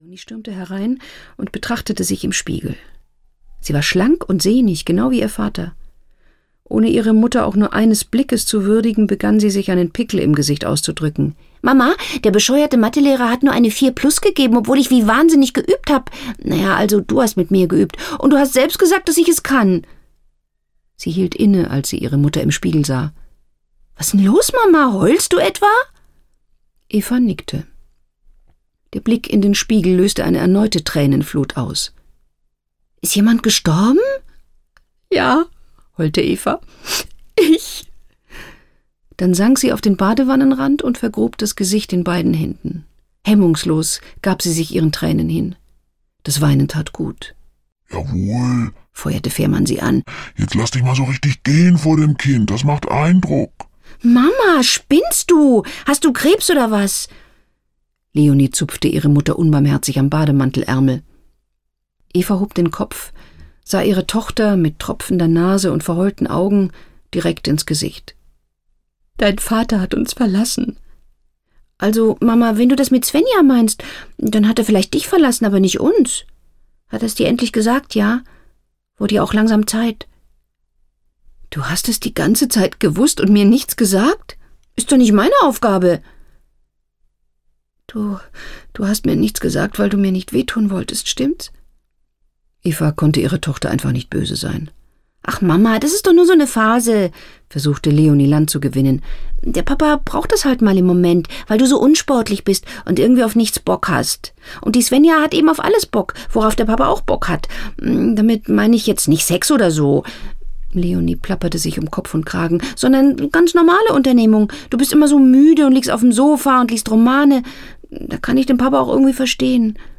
Die Champagner-Diät - Hera Lind - Hörbuch